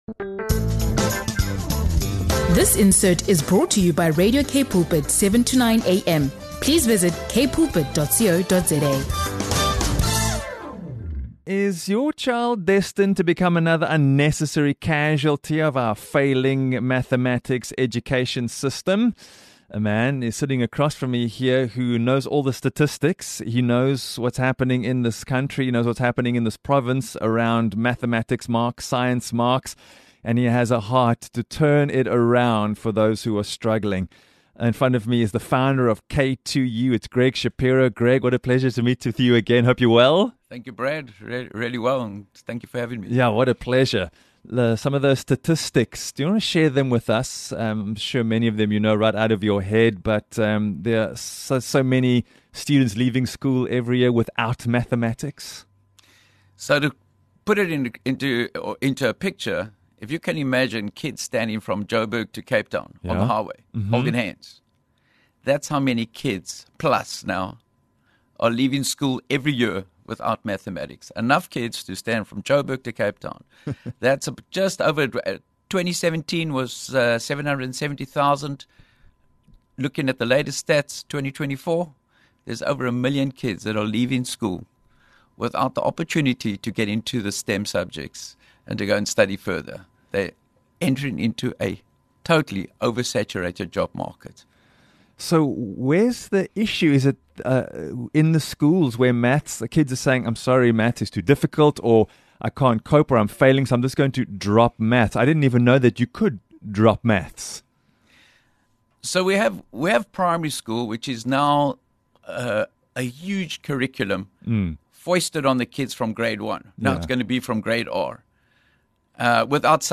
In this interview with Radio Cape Pulpit